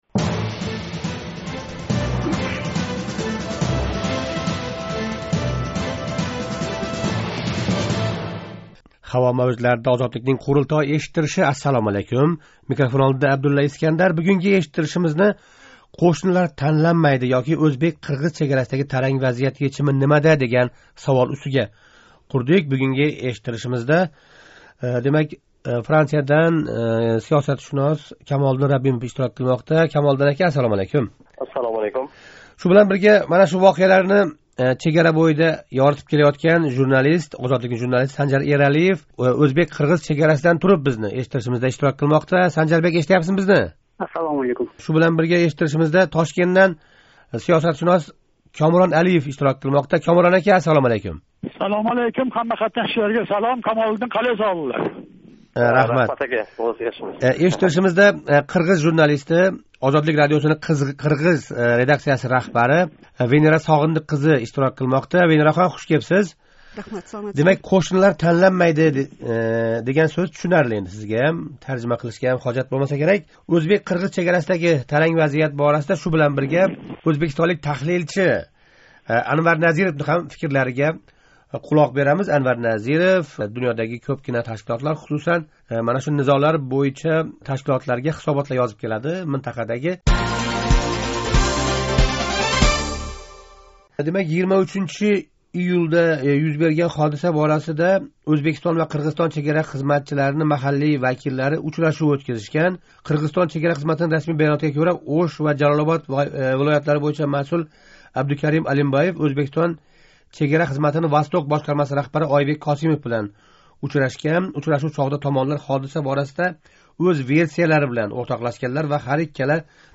Ўзбекистон-Қирғизистон чегарасида рўй берган навбатдаги можаро оқибатида икки ўзбек чегарачиси ўлдирилди. Шу муносабат билан "Қурултой" иштирокчилари қўшнилар ўртасига низо солиб келаëтган бундай ҳодисалар моҳиятини таҳлил қилдилар.